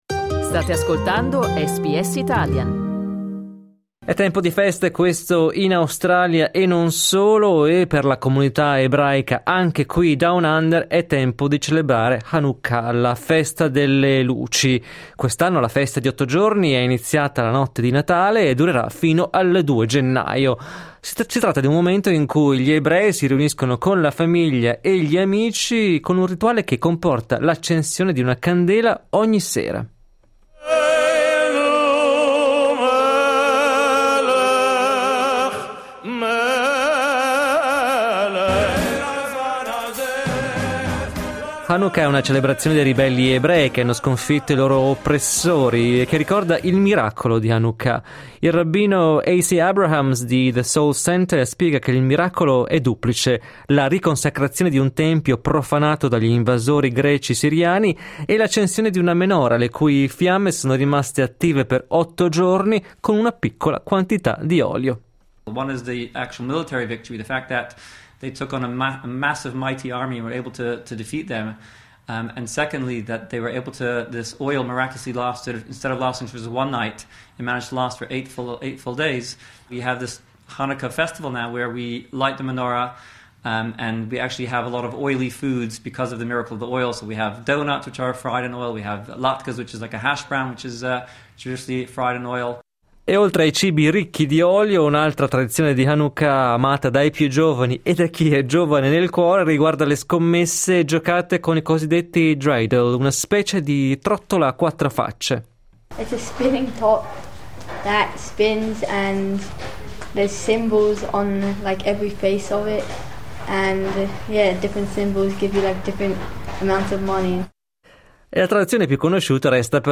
Ascolta il nostro servizio sull'Hanukkah cliccando 'play' in alto